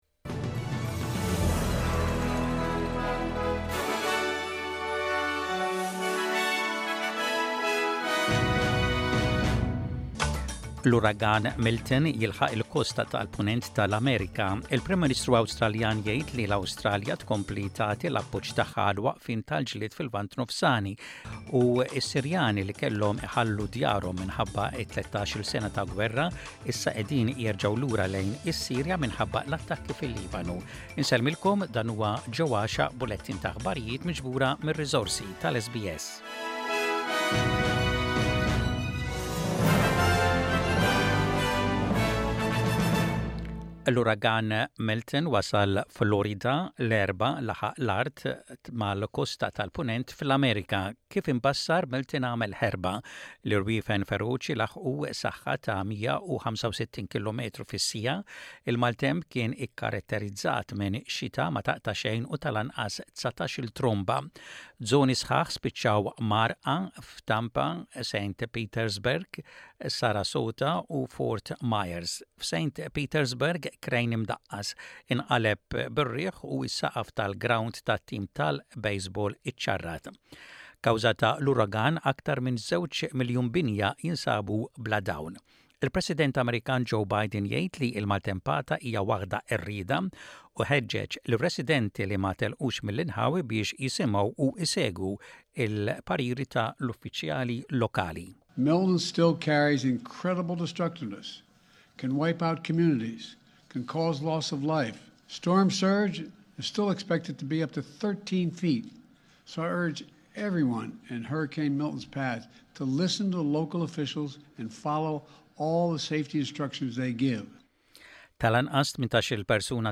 SBS Radio | Aħbarijiet bil-Malti: 11.10.24